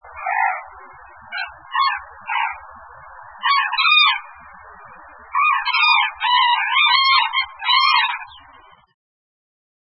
25c　鳥の鳴声その２
〔タンチョウ〕クルルルー（クワーカッカッ）／湿原に棲息，稀・北海道東部のみの留